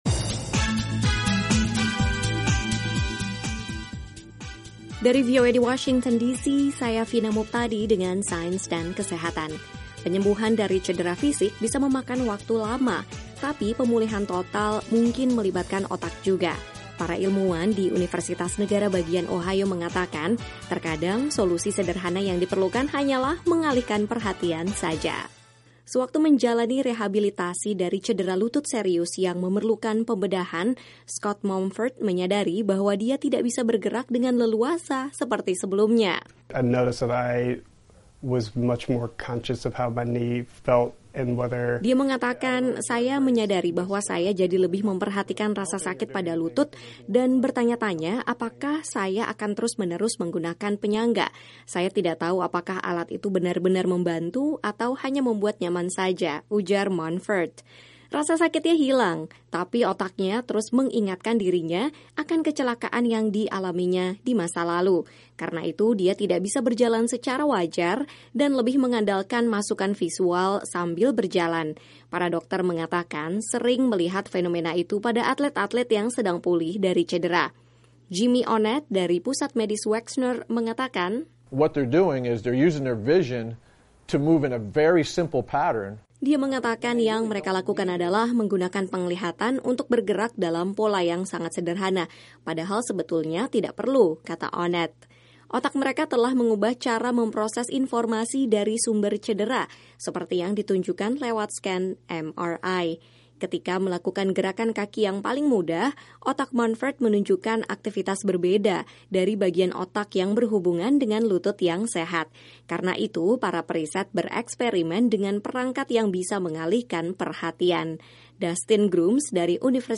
Penyembuhan dari cedera fisik bisa memakan waktu lama, tetapi pemulihan total mungkin melibatkan otak juga. Para ilmuwan di Universitas negara bagian Ohio mengatakan solusi sederhana yang diperlukan adalah ‘mengalihkan perhatian’ saja. Laporan VOA